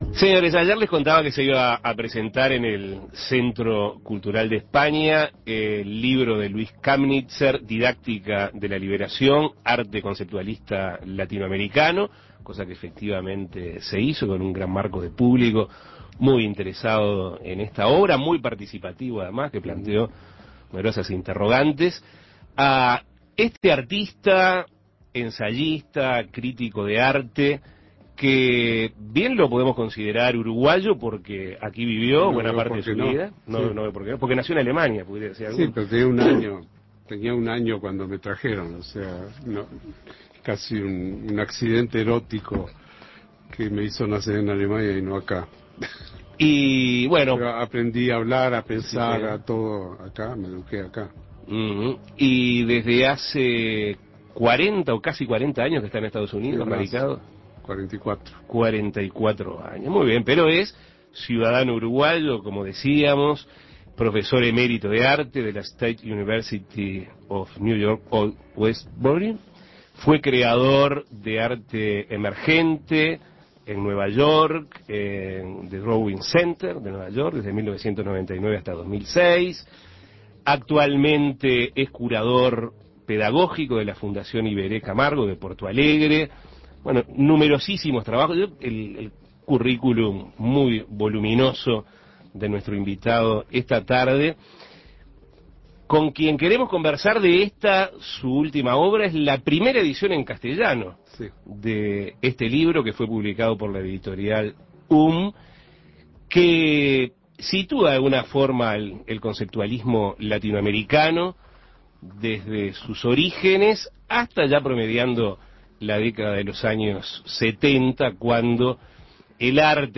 El artista Luis Camnitzer fue entrevistado en Asuntos Pendientes respecto a su nueva obra: Didáctica de la liberación: arte conceptualista latinoamericano. Esta es la primera edición en castellano que sitúa la historia del conceptualismo latinoamericano desde sus orígenes hasta las décadas del 60 y 70.
Entrevistas